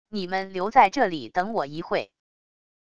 你们留在这里等我一会wav音频生成系统WAV Audio Player